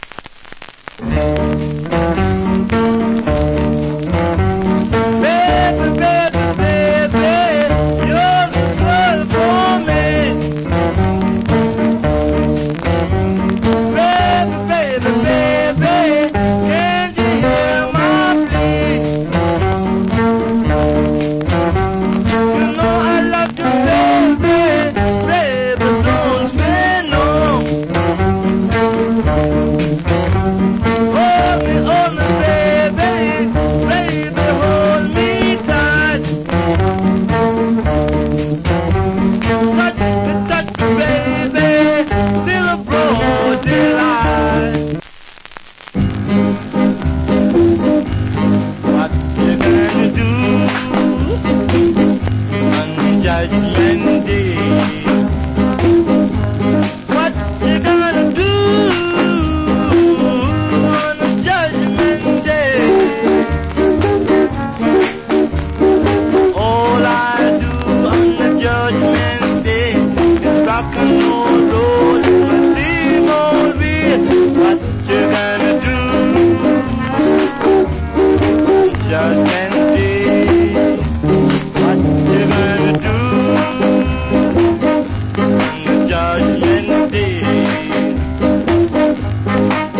Nice Jamaican R&B Vocal.